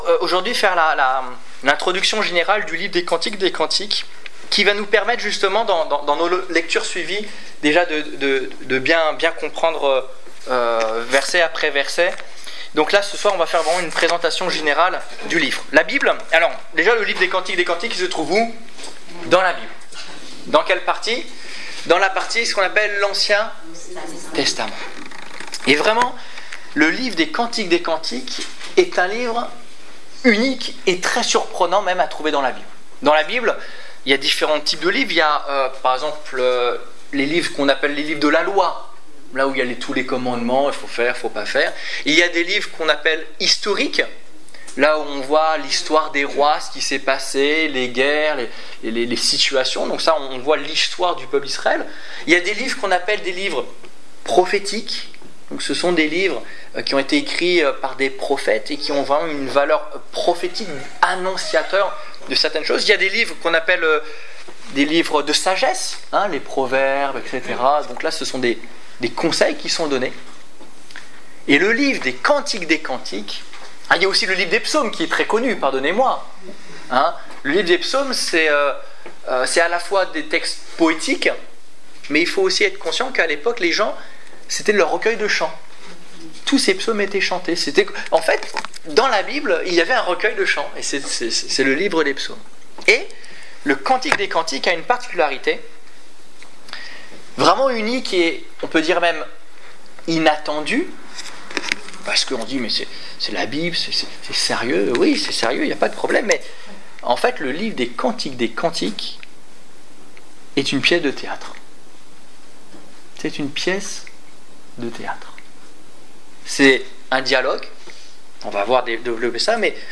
Cantique des Cantiques : Introduction Détails Prédications - liste complète Étude biblique du 17 juin 2015 Ecoutez l'enregistrement de ce message à l'aide du lecteur